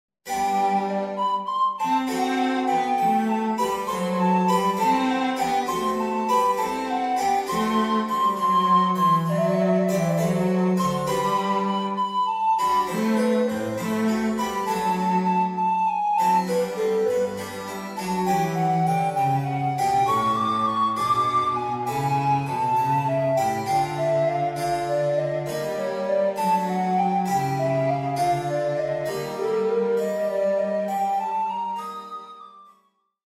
More baroque titles
Short extract from I Allegro